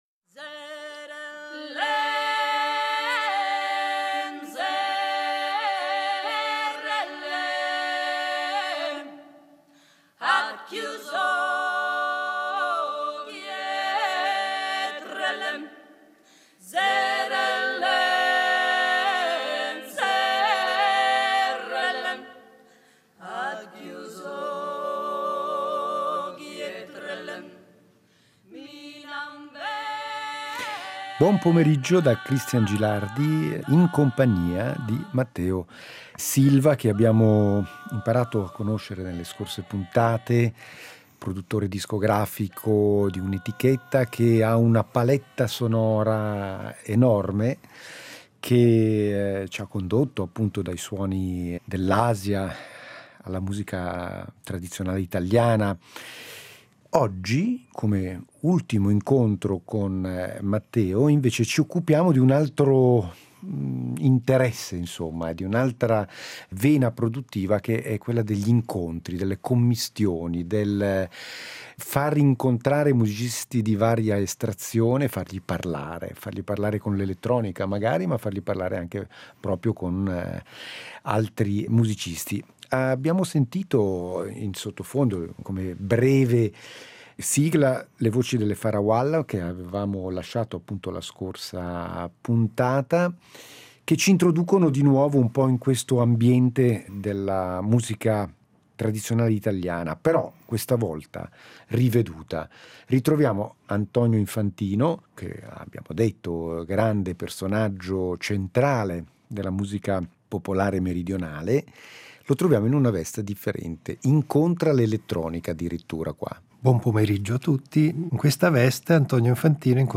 una lunga intervista, in quattro parti